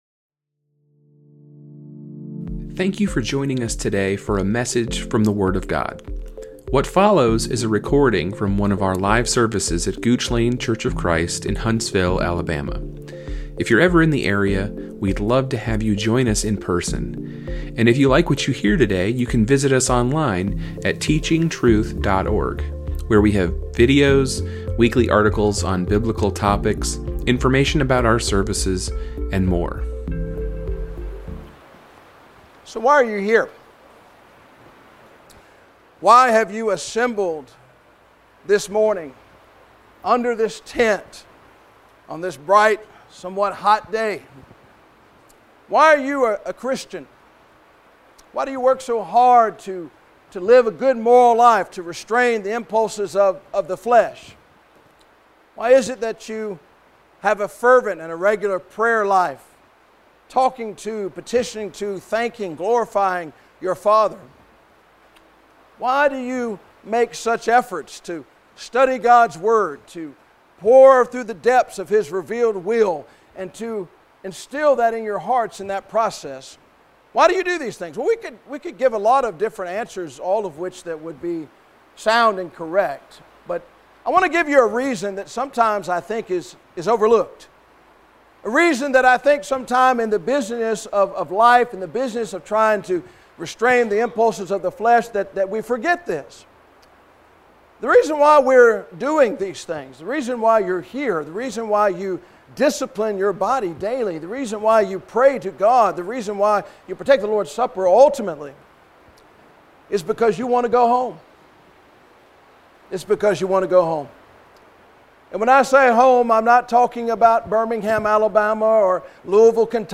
The fourth and final lesson in our Gospel Meeting